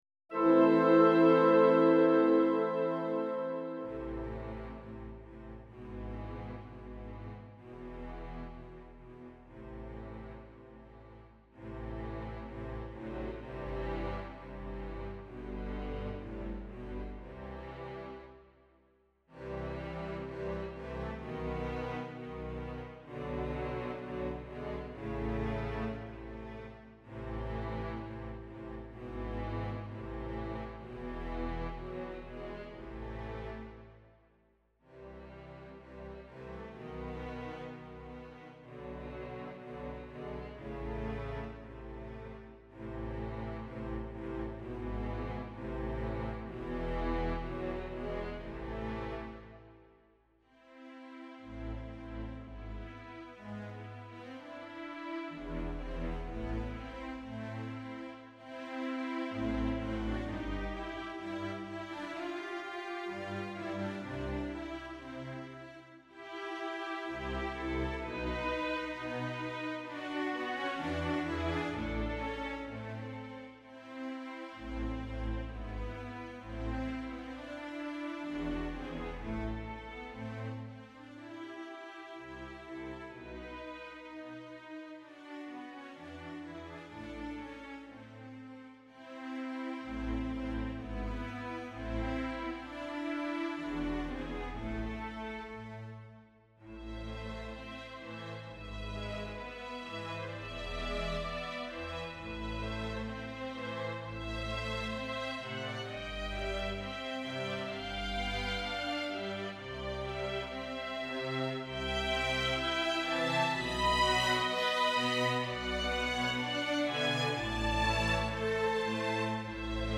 This is a MIDI performance of music drawn from the symphonies of the lovely, lovely Ludwig van. It is my second attempt at classical orchestral music, and was done the same way as the first.